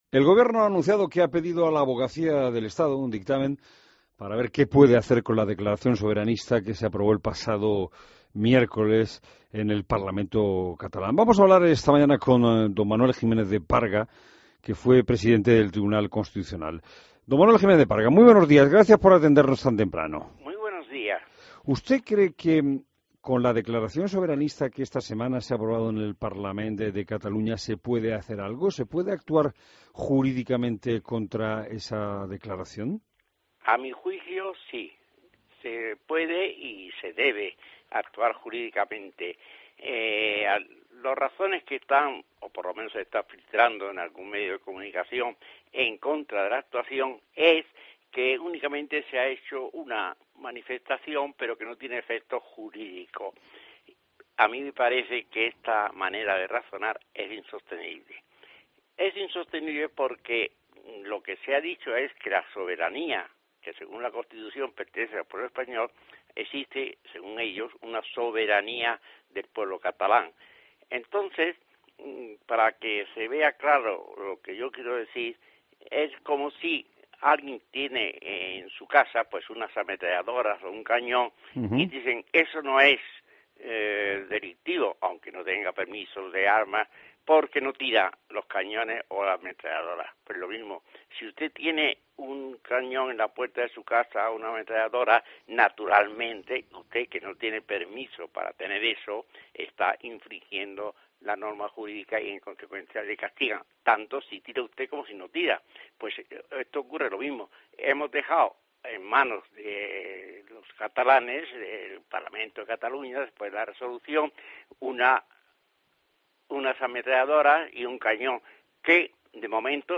Escucha la entrevista al ex presidente del TC, Manuel Jiménez de Parga